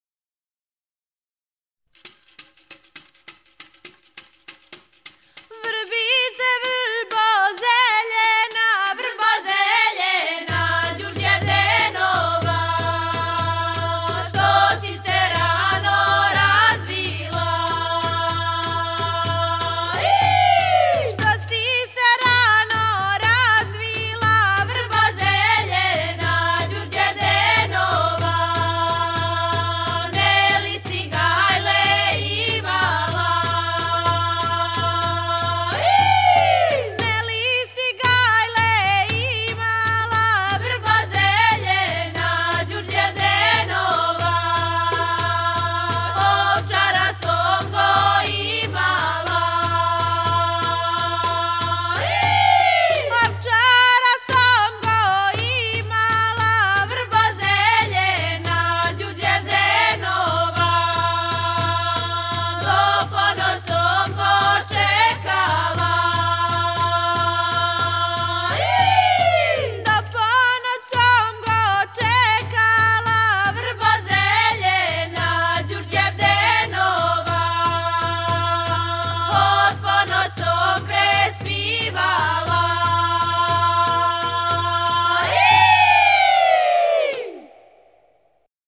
Женска група
Порекло песме: Призренска Гора, Косово и Метохија